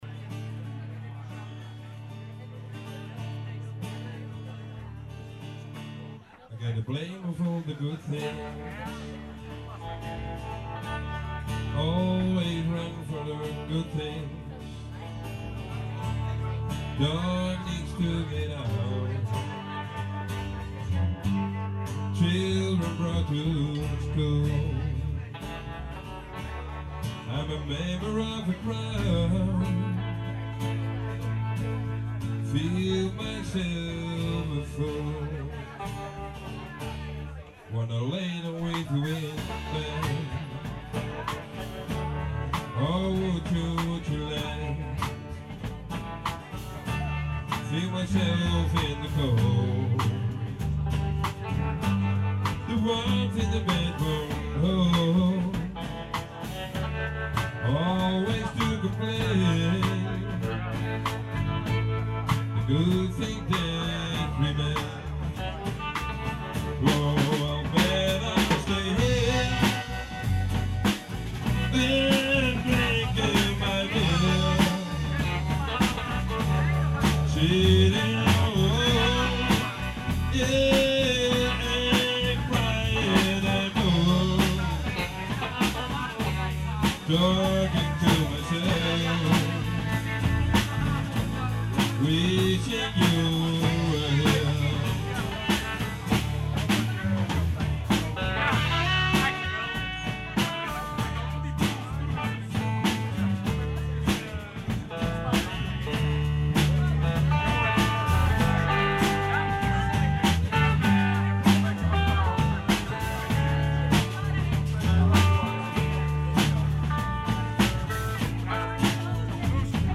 some live  MP3  files